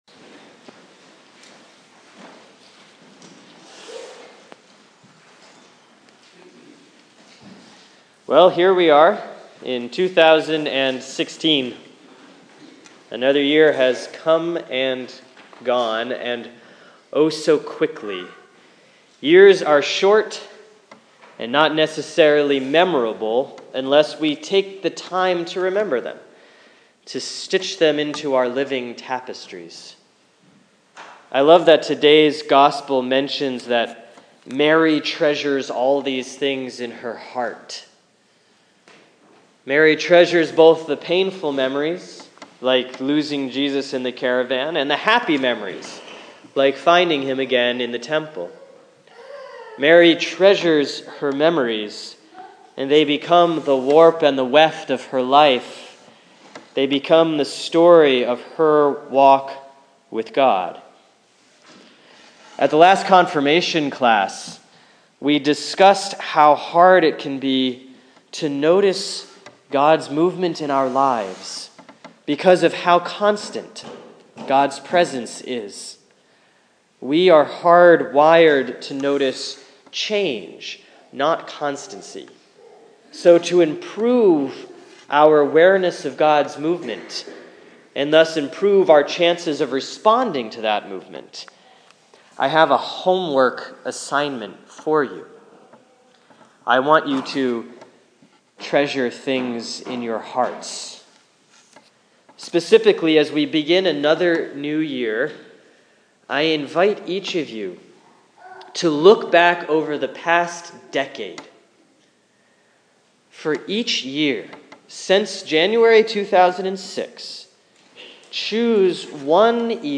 Sermon for Sunday, January 3, 2016 || Christmas 2 || Luke 2:41-52